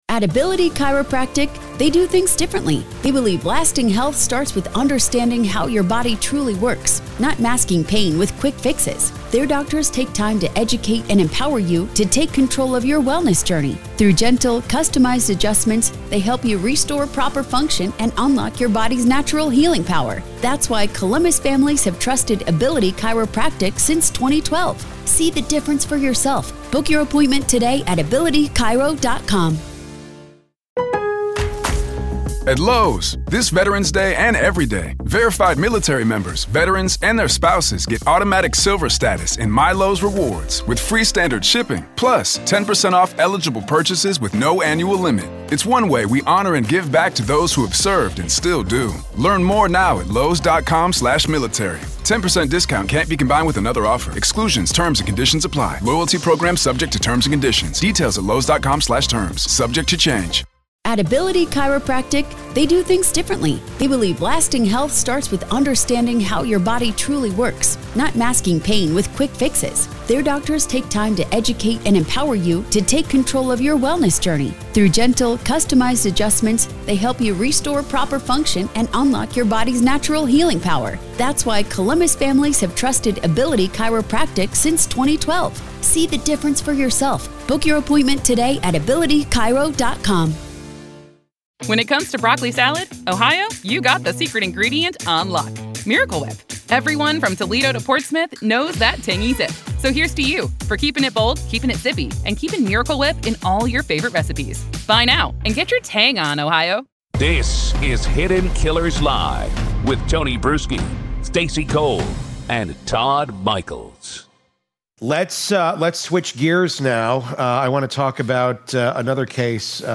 Subscribe for more true-crime conversations that expose the system behind the stories.